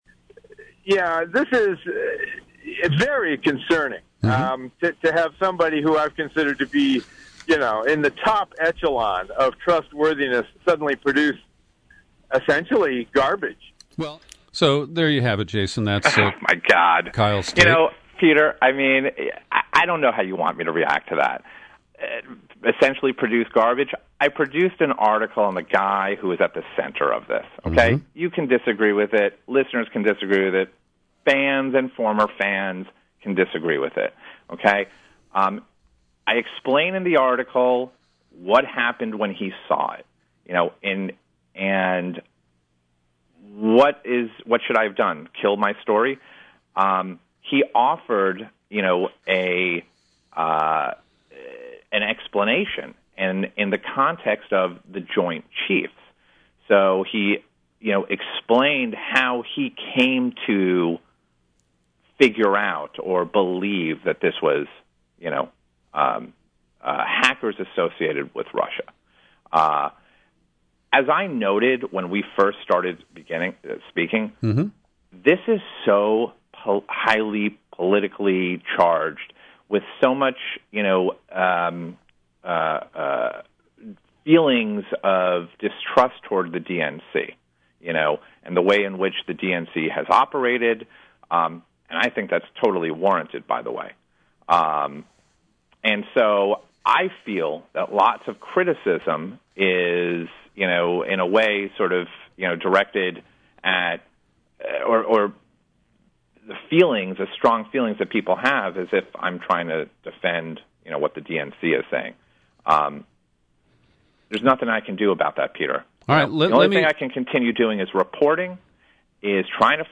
In-Depth Interview